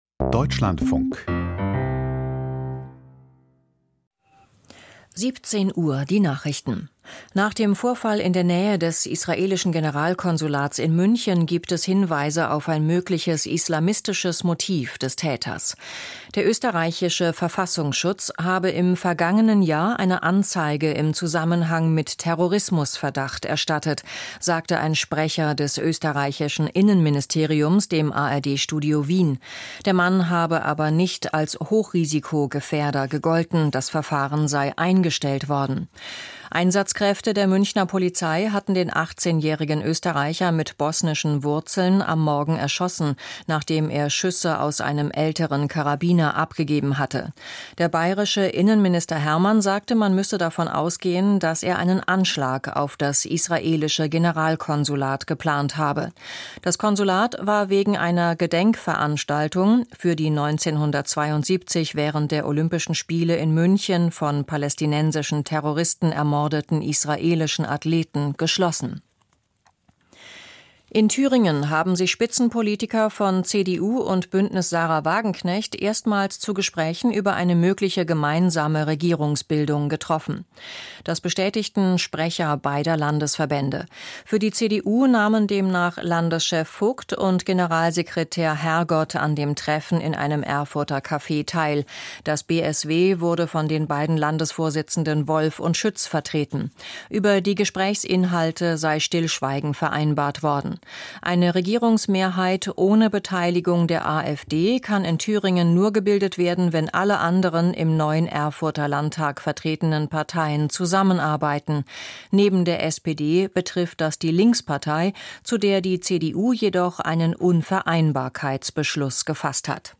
Die Deutschlandfunk-Nachrichten vom 05.09.2024, 17:00 Uhr
Die wichtigsten Nachrichten aus Deutschland und der Welt.